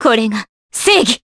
Scarlet-Vox_Skill5_Jp.wav